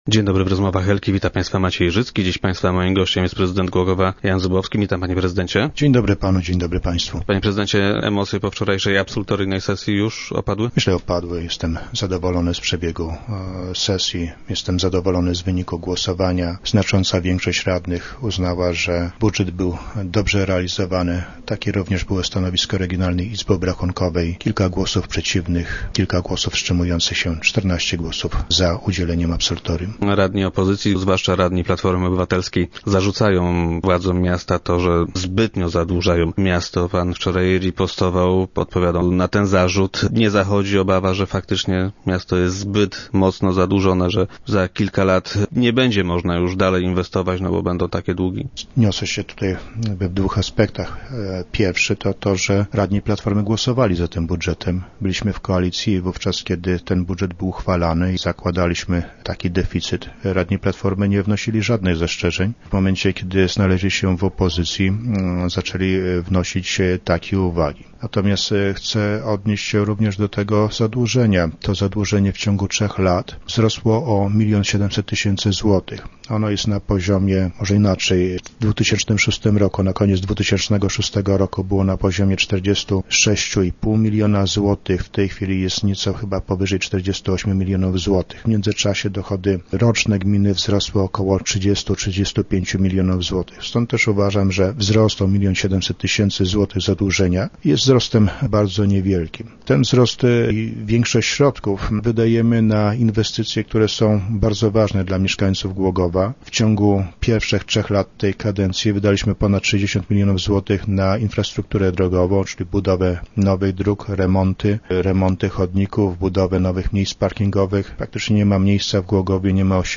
- Te zarzuty są absurdalne - twierdzi prezydent, który był dziś gościem Rozmów Elki.